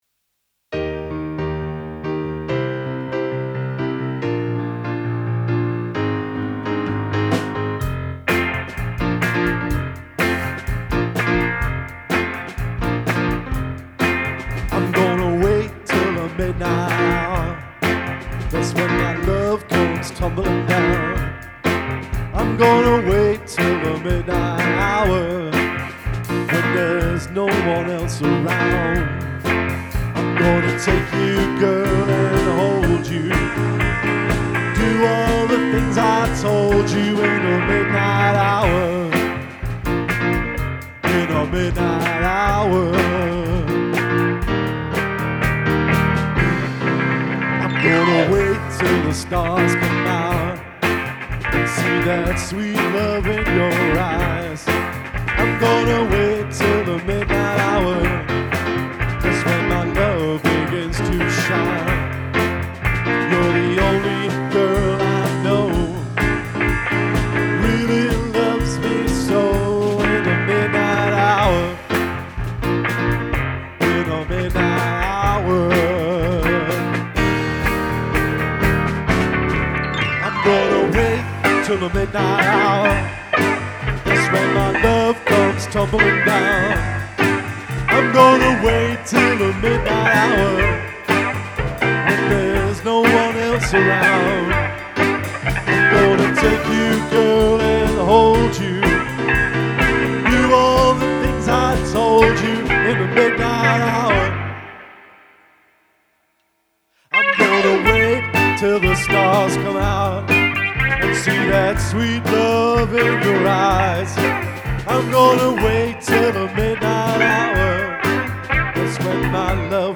has some fun in the 'free' jazz bit ...